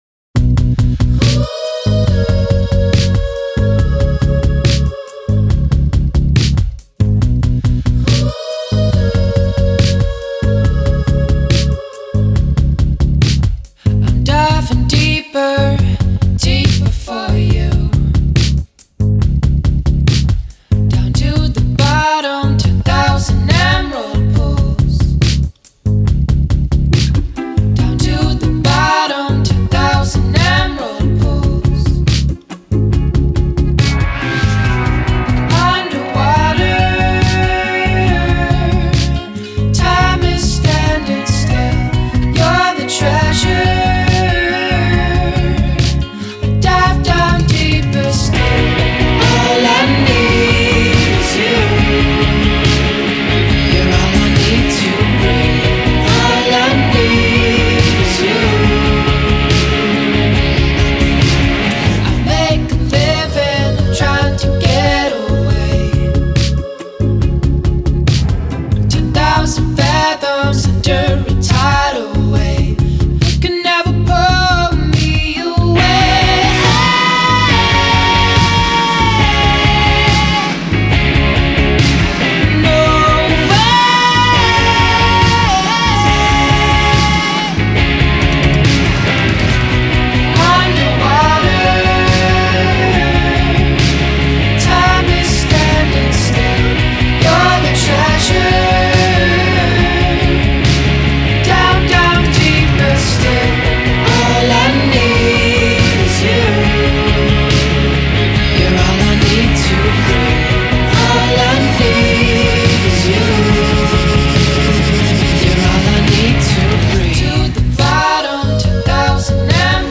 psychedelically sweet